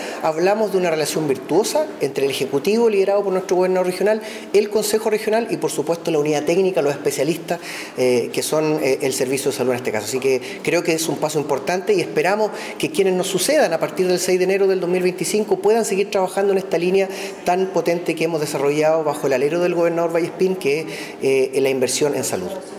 Por su parte, el gobernador regional subrogante, Luciano Belmar, manifestó que se debe destacar la inversión realizada por el Gore para la salud integral de la población.